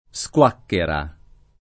SkU#kkera]) s. f. — voce pop. per «diarrea» con vari sensi figurati (spregiativi) — da una var. dial. il nome di Meo Squaquara